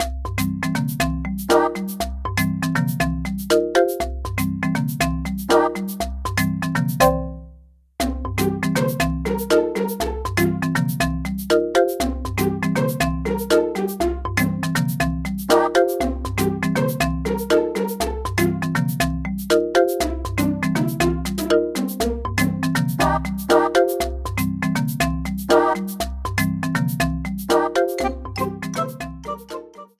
applied fade-out